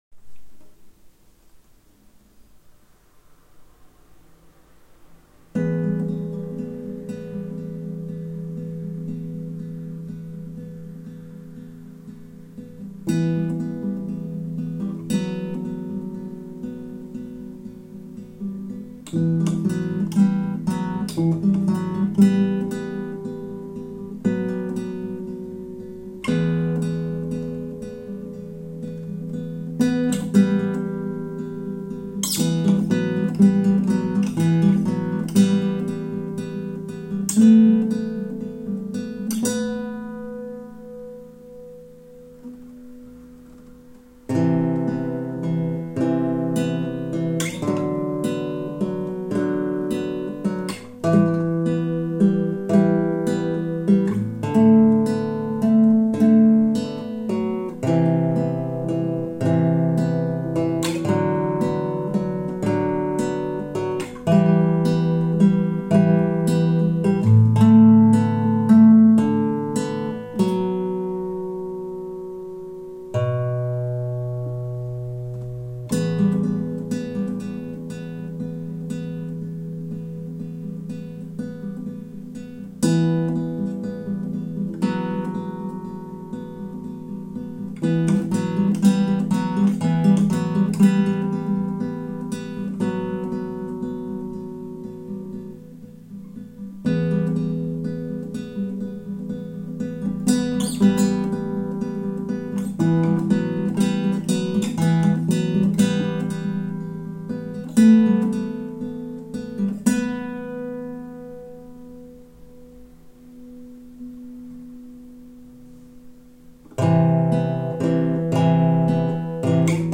Самоучка..